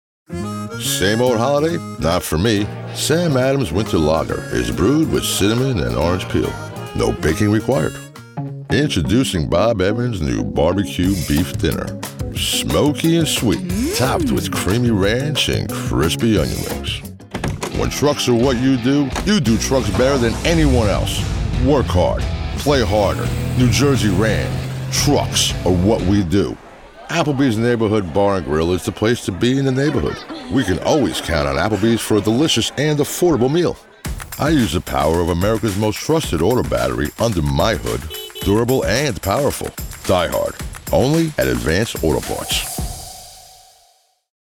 Browse professional voiceover demos.